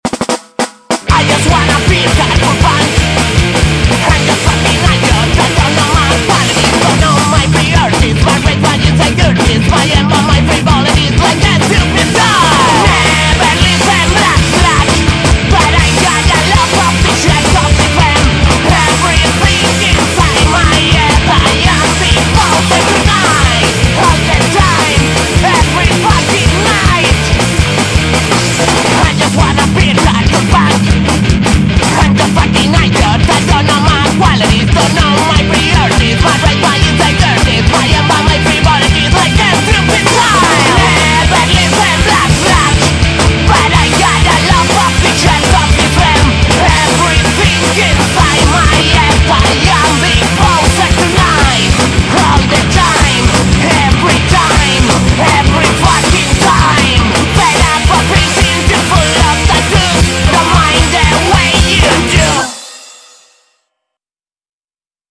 Estilo: Punk Rock